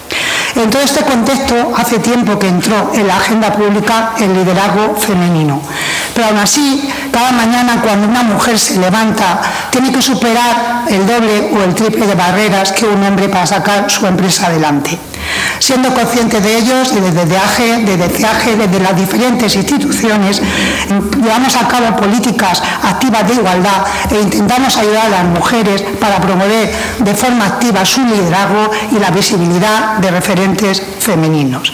La delegada provincial de Igualdad, Lola Serrano, ha participado en la presentación del Primer Congreso de Liderazgo Femenino en Albacete.